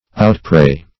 Outpray \Out*pray"\
outpray.mp3